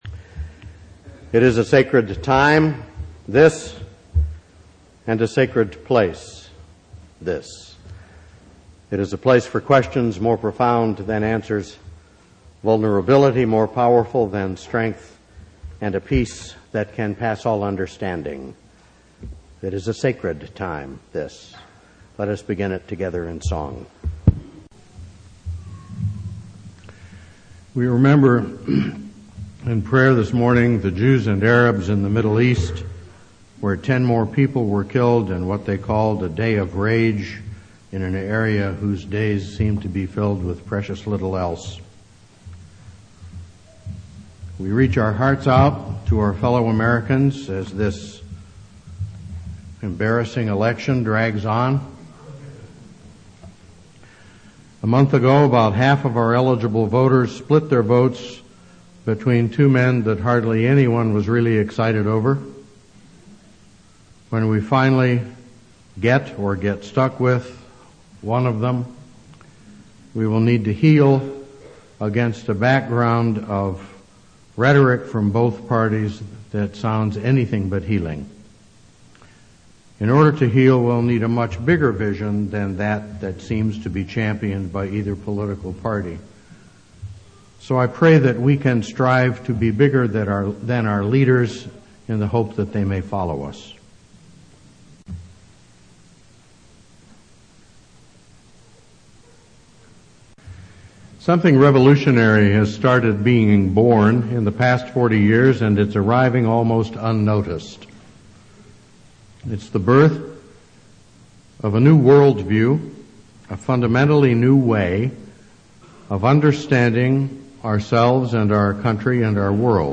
2000 Listen to the sermon by clicking the play button. These are remarks in response to the book The Cultural Creatives: How Fifty Million People Are Changing the World by Paul Ray and Sherry Anderson.